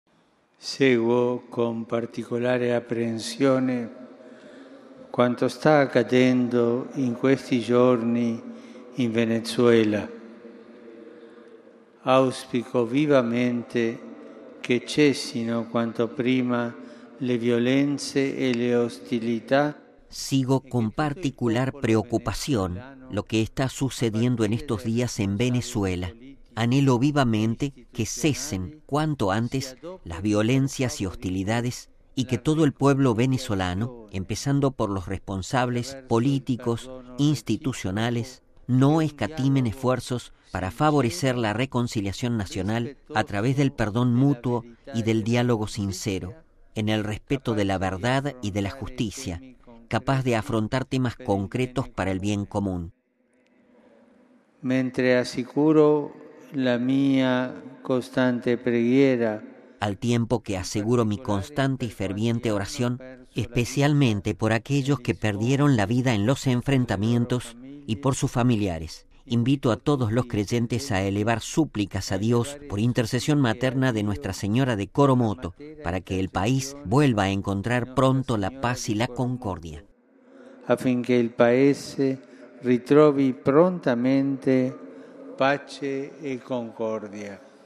(RV).- ( se actualizó con voz del Papa y video) RealAudio